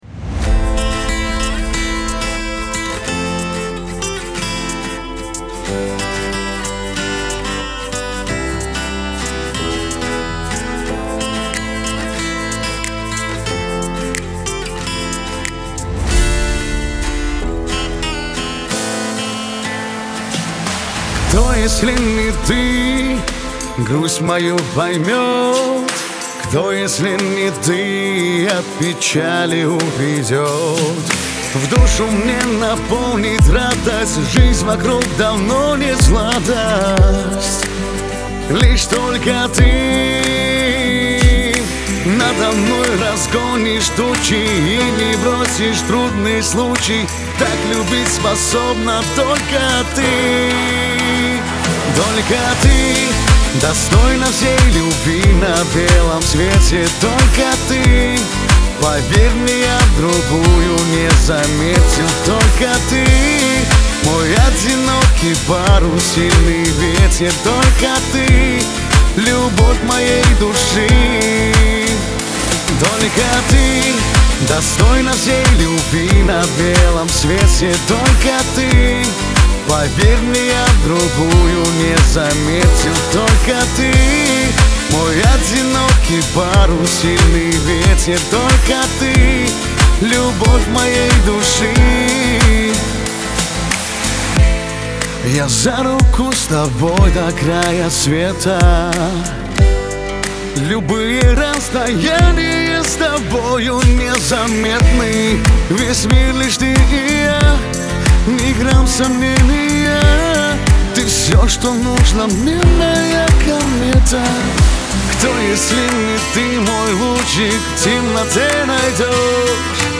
Лирика
Кавказ поп